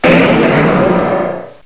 cry_not_mega_kangaskhan.aif